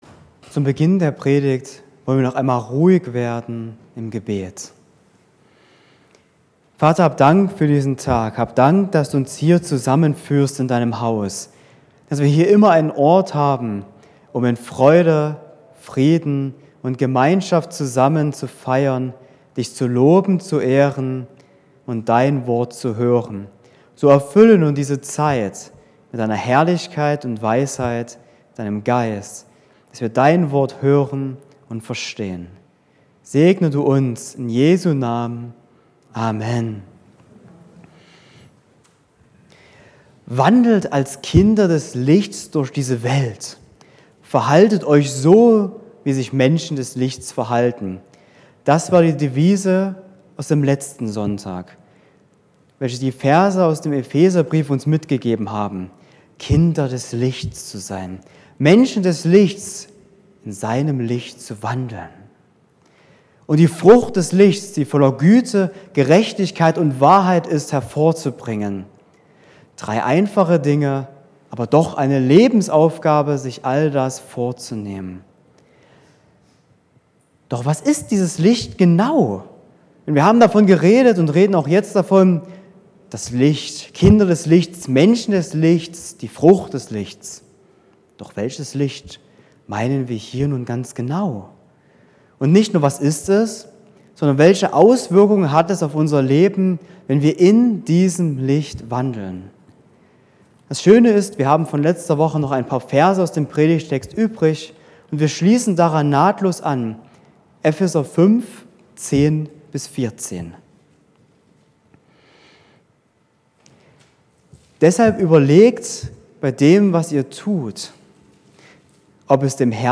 Predigten
Einer redet und viele hören zu.